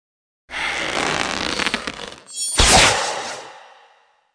SFX弓箭用力张开并射出正中靶心音效下载
SFX音效